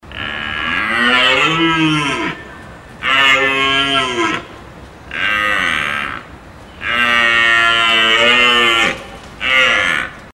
рев оленя